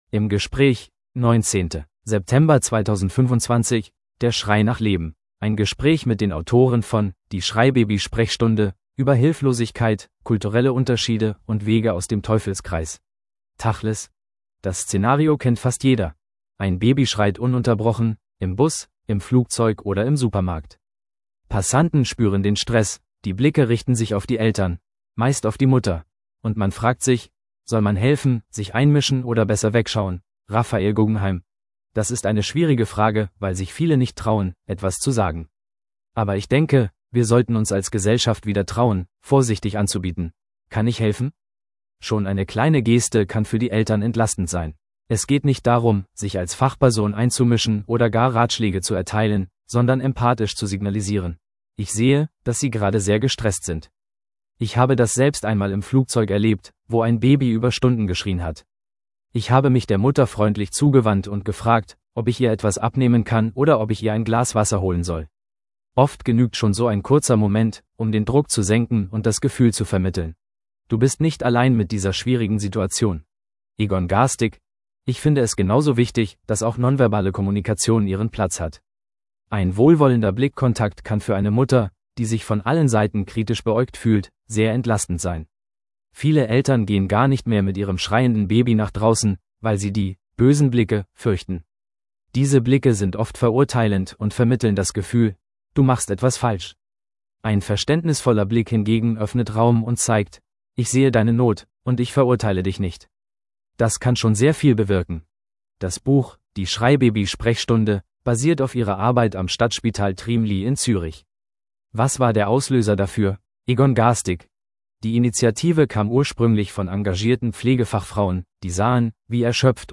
im Gespräch 19.